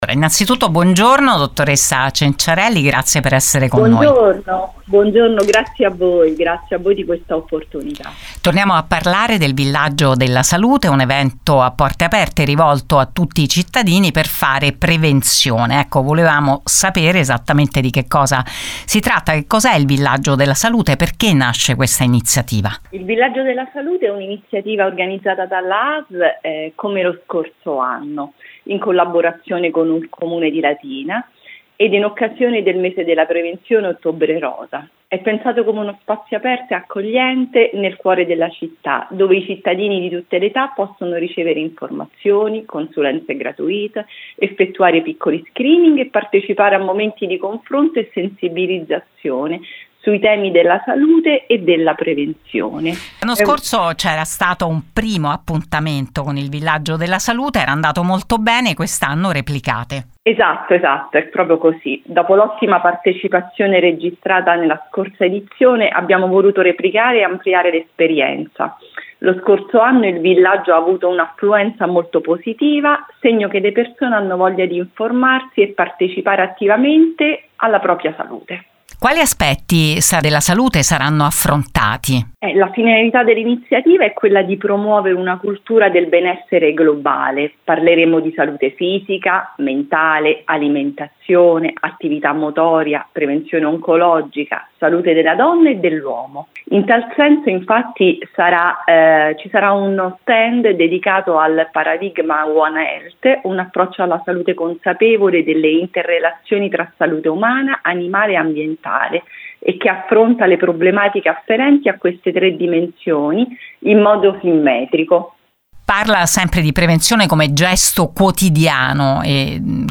Un’iniziativa corale rivolta a tutti, perché – spiega la direttrice generale della Asl di Latina Sabrina Cenciarelli “prendersi cura di sé è il primo passo per stare bene” come ha raccontato a Gr Latina su Radio Immagine, Radio Latina e Radio Luna.